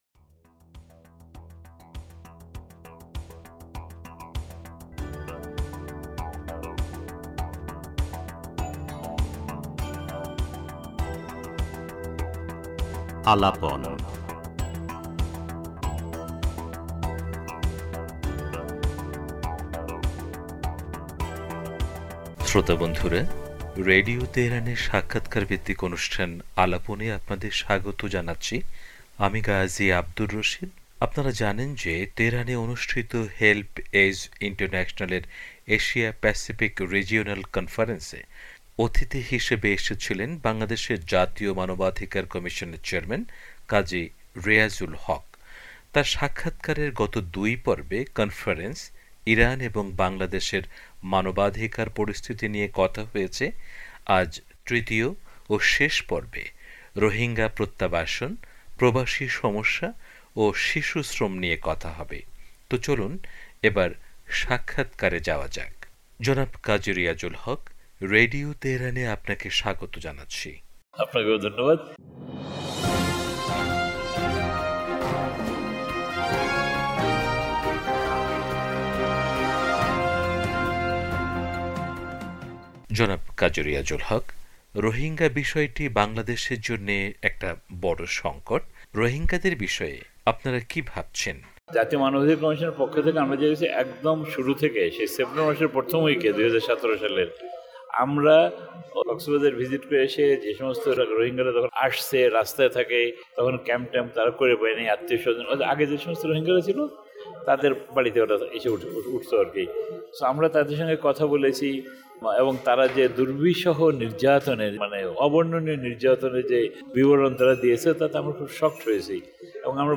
তাঁর সাক্ষাৎকারের শেষপর্বে রোহিঙ্গা প্রত্যাবাসন, প্রবাসীদের সমস্যা ও শিশুশ্রম নিয়ে কথা বলেছেন রেডিও তেহরানের সাথে। তিনি বলেছেন, মিয়ানমারে মানবাধিকার বলে কিছু আছে বলে তাঁর জানা নেই। আর আন্তর্জাতিকভাবে চাপ প্রয়োগ করা ছাড়া সম্মানজনক রোহিঙ্গা প্রত্যাবাসন সম্ভব নয়।